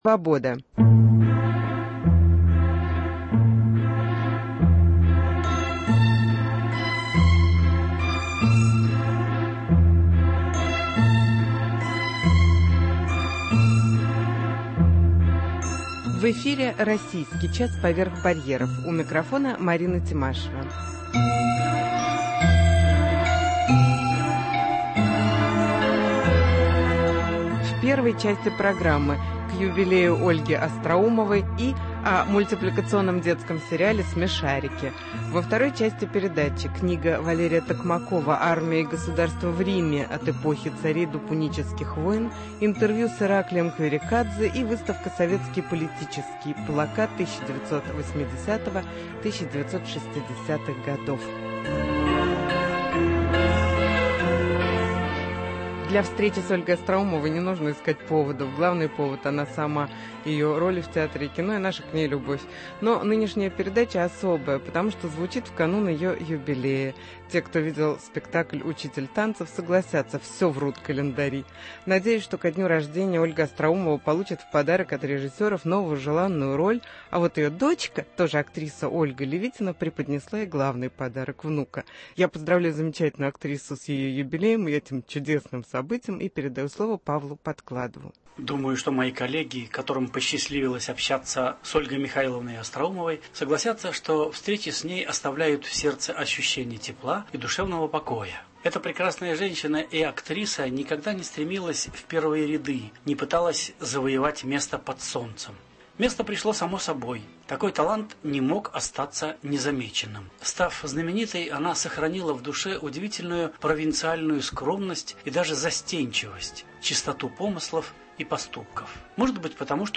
Интервью с Ольгой Остроумовой